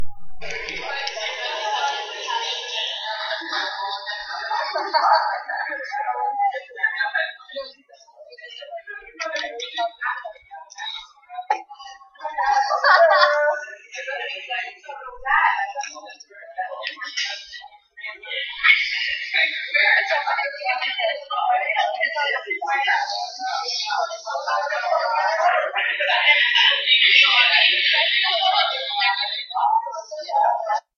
Distortion – Hofstra Drama 20 – Sound for the Theatre
Door being opened, propped, and shut , TV, Ventilation, Toilet, Hing Creak